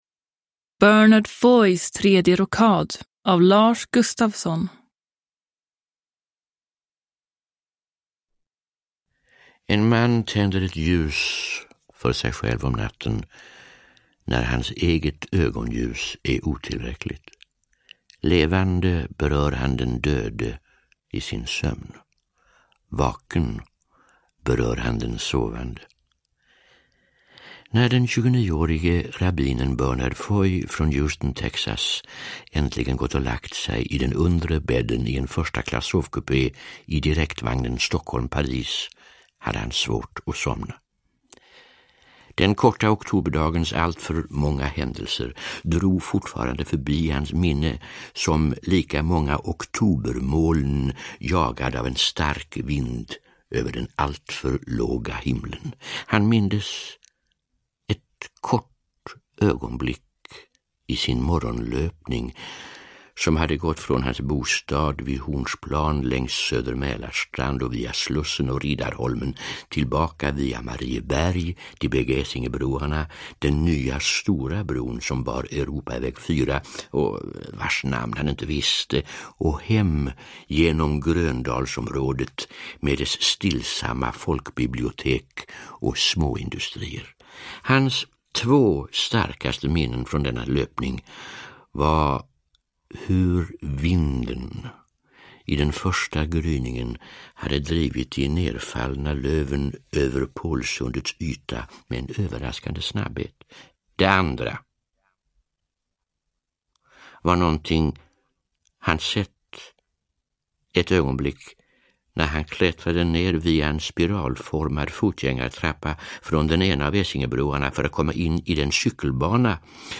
Bernard Foys tredje rockad (ljudbok) av Lars Gustafsson